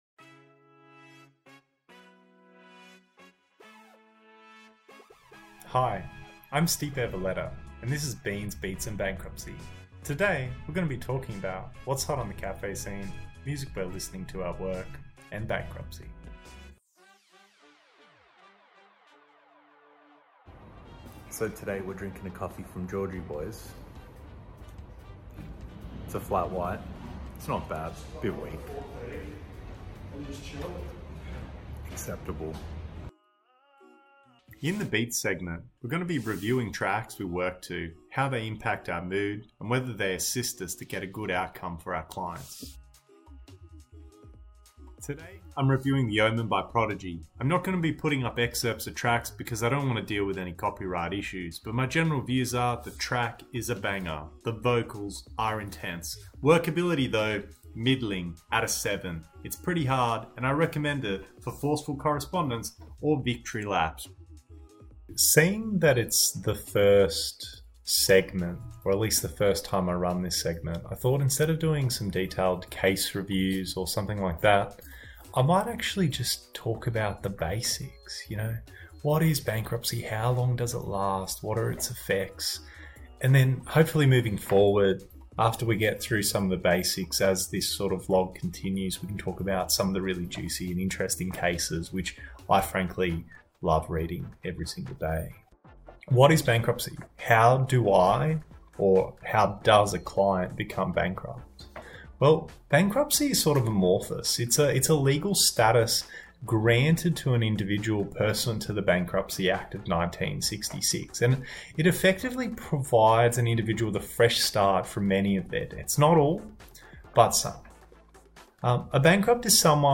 In this insolvency video blog we get to learn the basics of bankruptcy with the addition of some good tunes and coffee tastings.